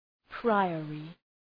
Προφορά
{‘praıərı}